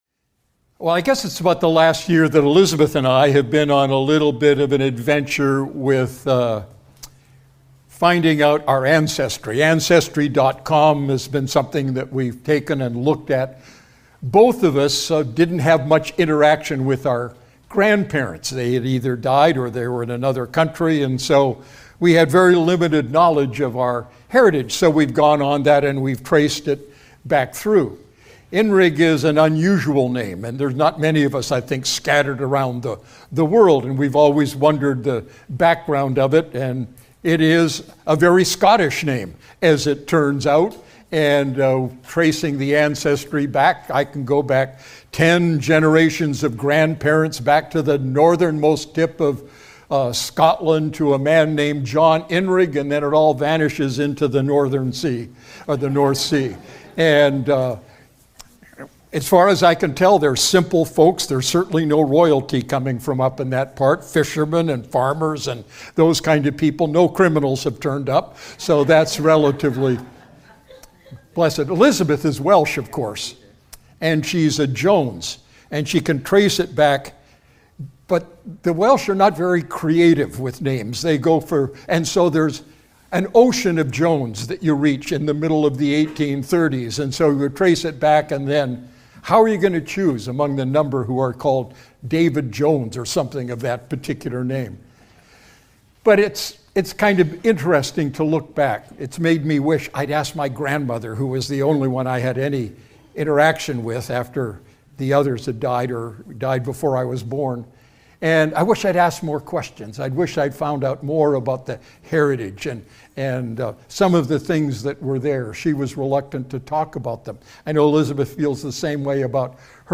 Sermon Archive | Redeemer Fellowship